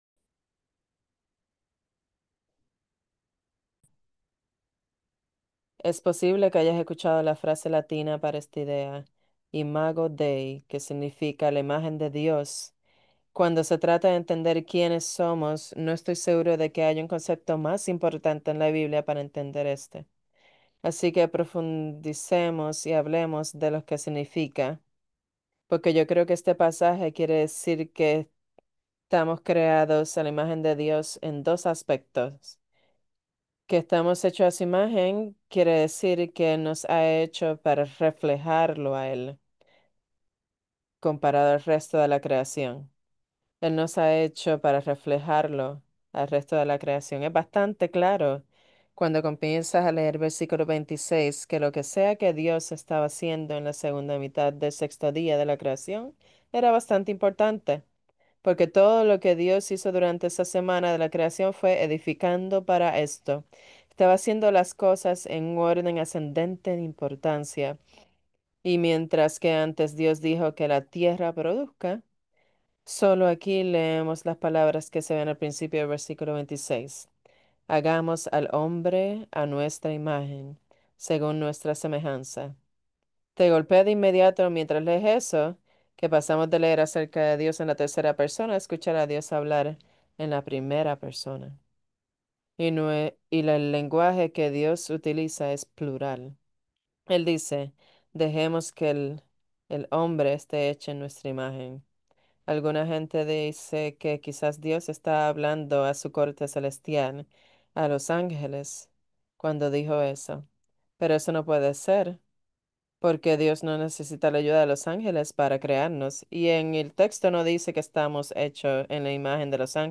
2025 Bulletin What does it mean to be made in the image of God? In this powerful sermon from Genesis 1:26–31